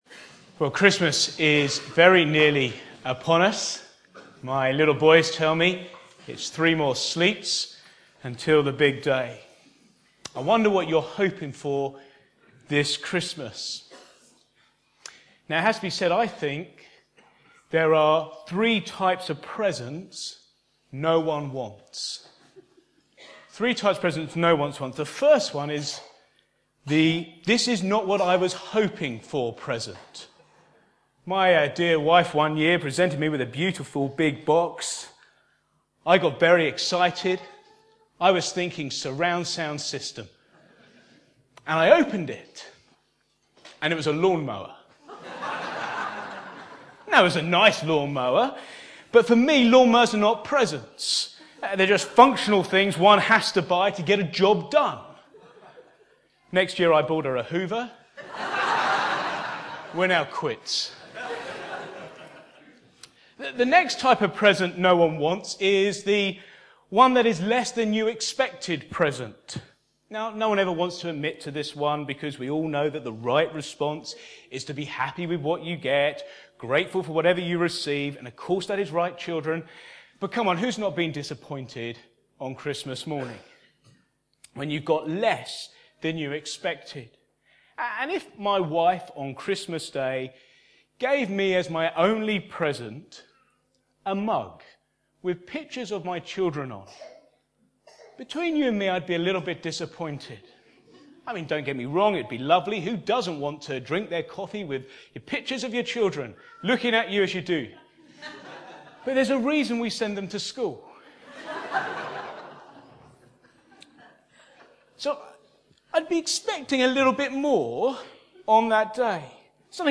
Back to Sermons The best present